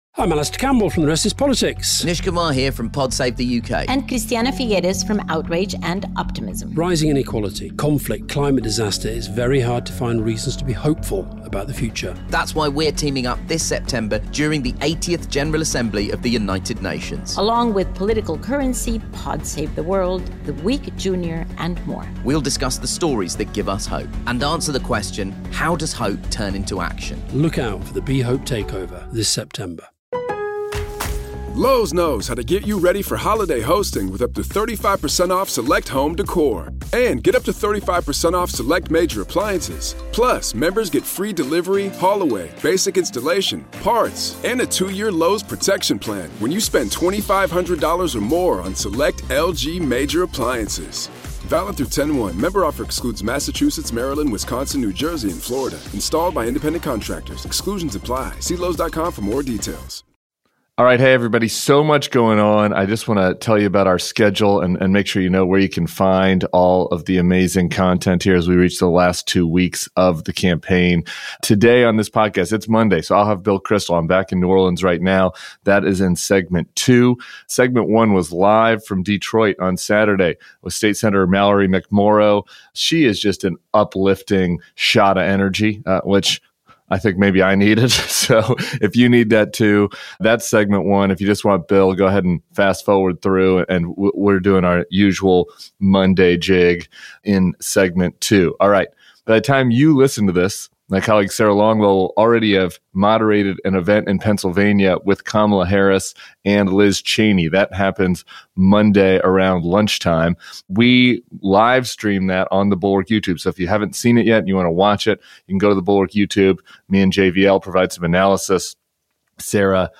Mallory McMorrow was recorded live at our Bulwark event in Detroit, and Bill Kristol joined Tim Miller for a recap of the weekend's political news.